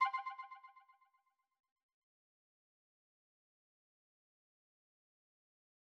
back_style_4_echo_001.wav